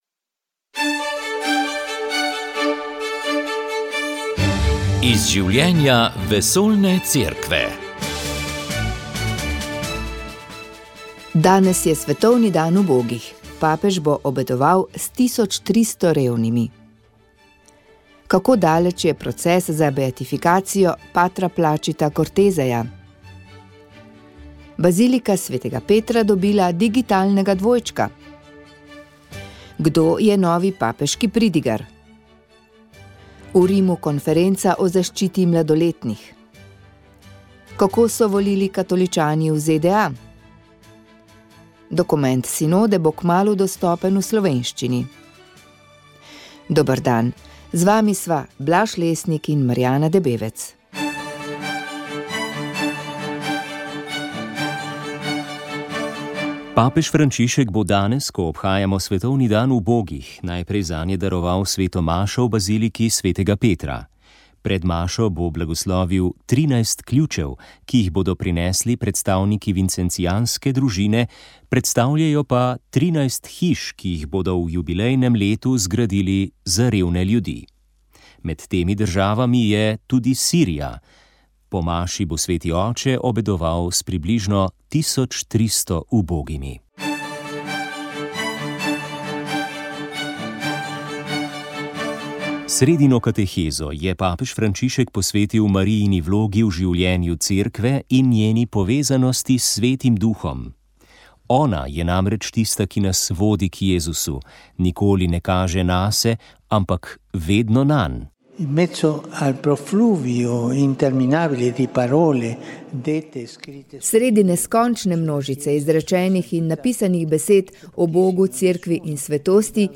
V oddaji iz življenja vesoljne Cerkve ste lahko slišali bistvene poudarke papeževega obiska Indonezije. V studio pa smo povabili štiri Marijine sestre iz Ukrajine, ki so nam povedale, kakšno je njihovo poslanstvo v tej deželi, ki izgublja upanje, da bo nekoč zavladal mir.